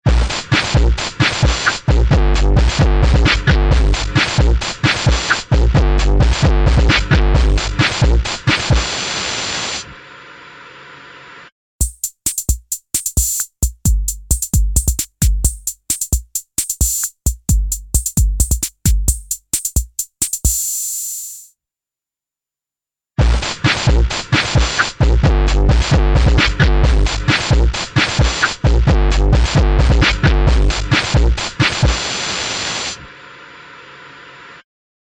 豊潤なリバーブと、引き裂くようなディストーション
MangledVerb | Drum Loop | Preset: Broken TV
MangledVerb-Drum-Loop-Preset-Broken-TV.mp3